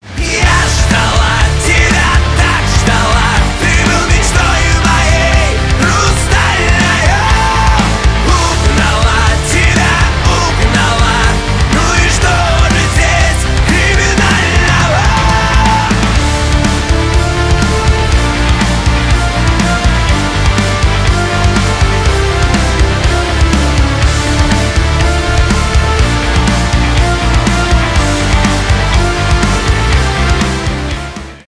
Скачать припев